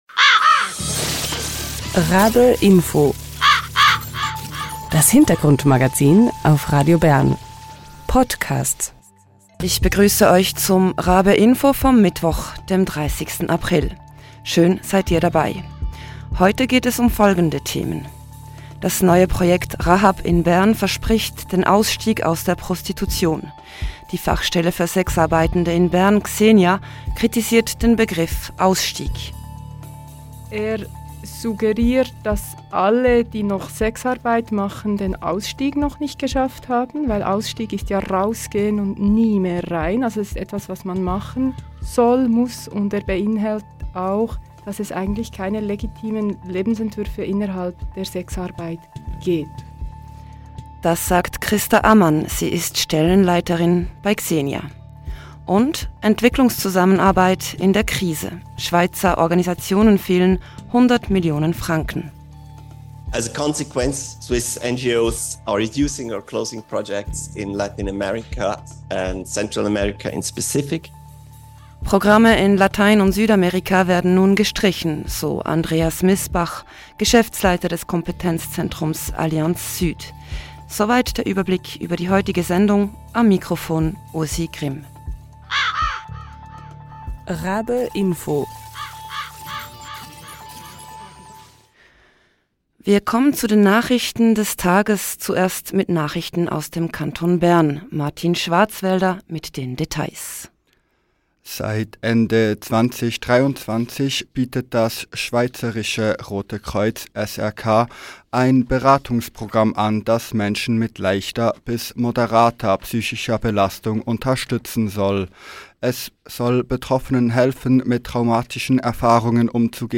Und: Die Entwicklungszusammenarbeit steckt in einer Krise: Die US Aid wurde unter Donald Trump zerschlagen, das Schweizer Parlament investiert lieber in die Armee statt in Entwicklungsprojekte: Was bedeutet das für Schweizer Entwicklungsorganisationen? Darüber haben gestern in Genf Vertreter:innen verschiedener Hilfswerke debattiert.